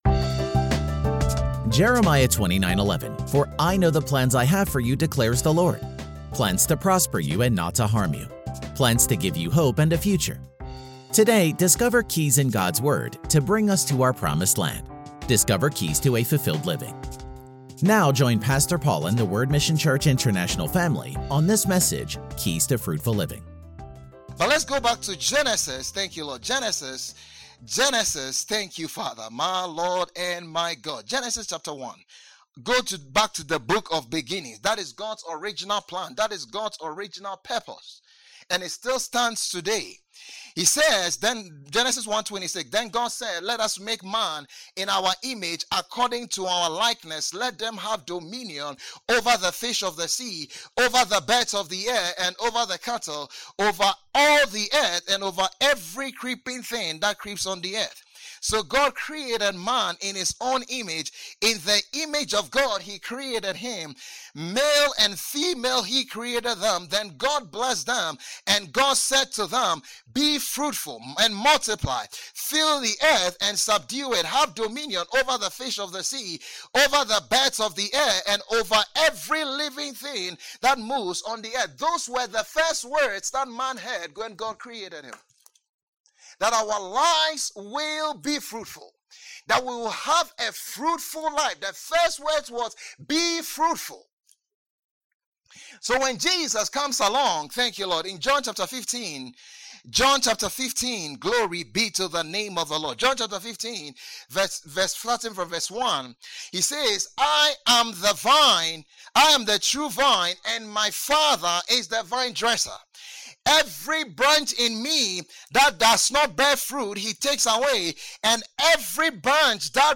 Post category: Sermon